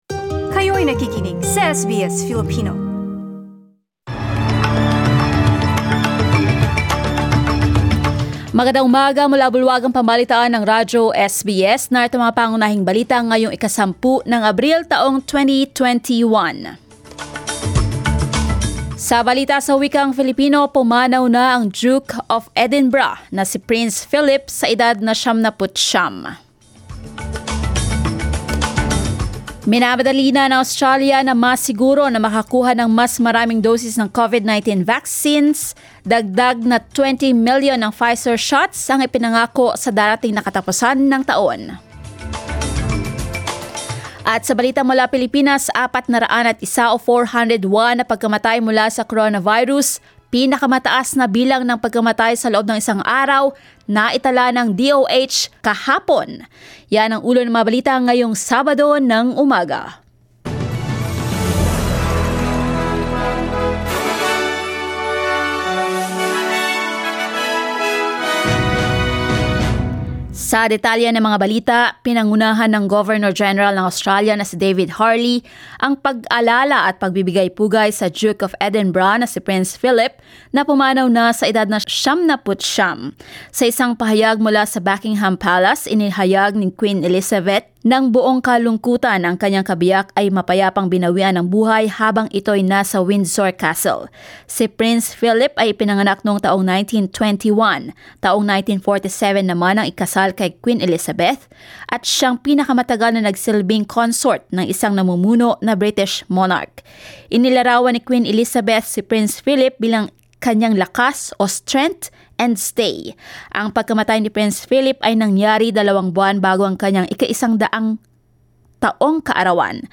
SBS News in Filipino, Saturday 10 April